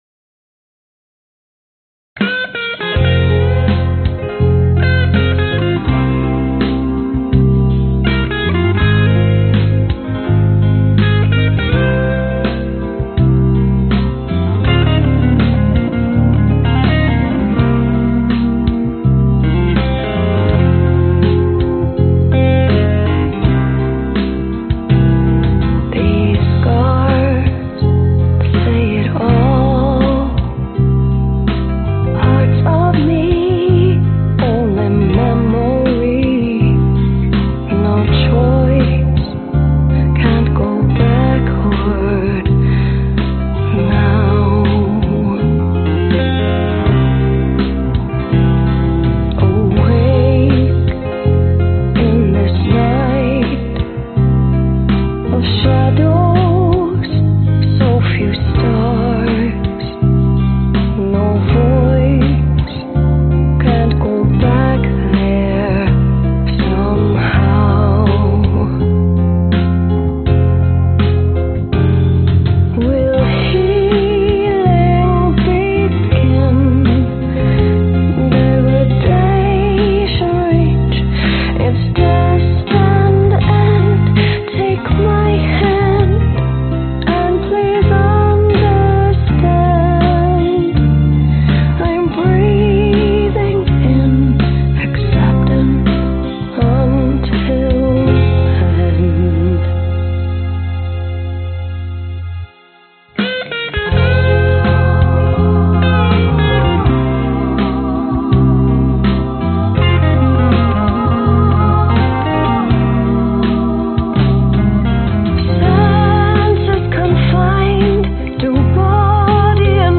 Tag: 女声 吉他 贝斯 钢琴 风琴 弦乐 交响乐